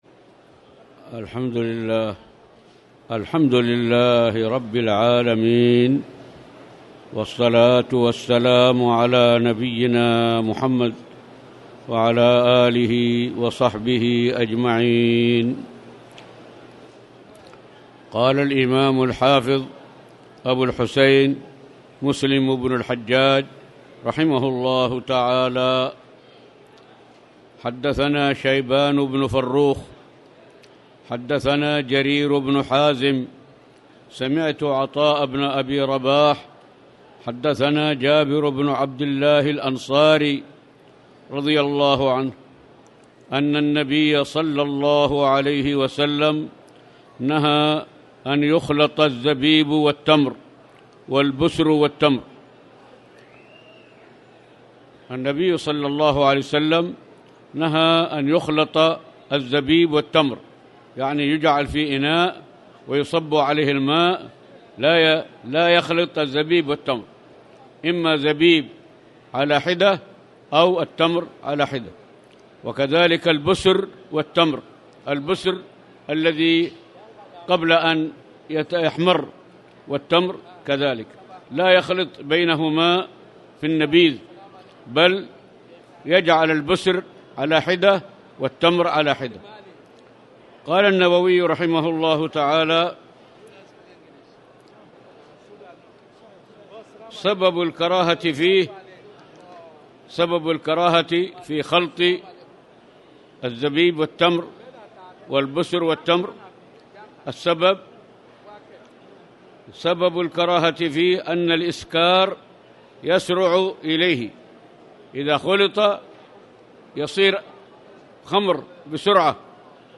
تاريخ النشر ٩ شوال ١٤٣٨ هـ المكان: المسجد الحرام الشيخ